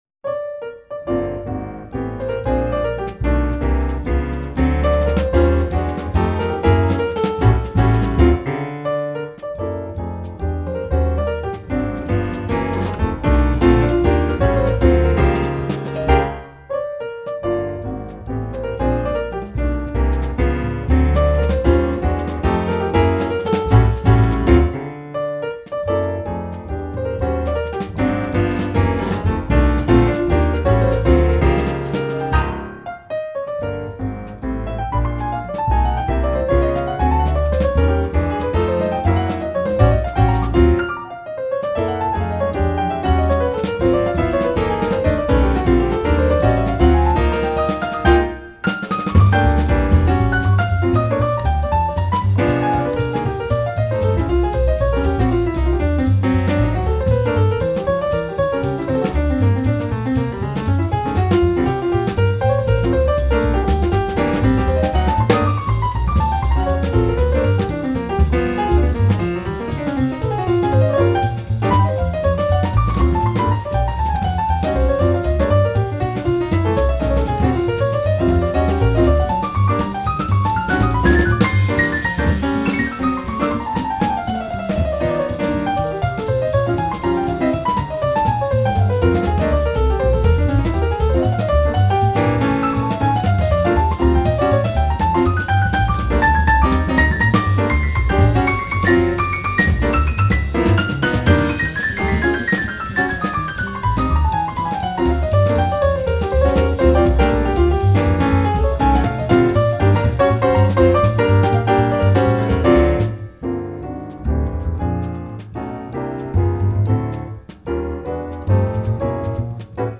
pianist
bass
drums